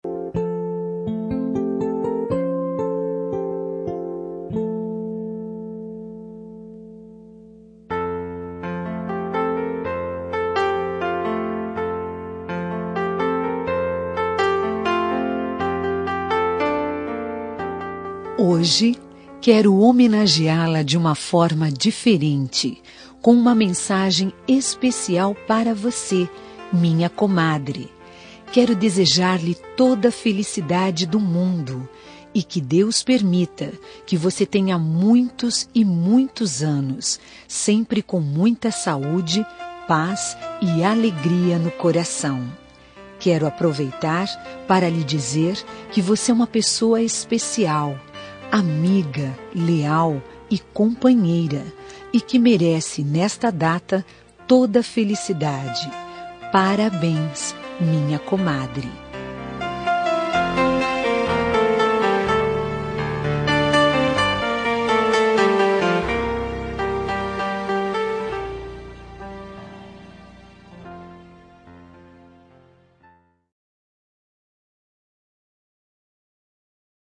Aniversário de Comadre – Voz Feminina – Cód: 202159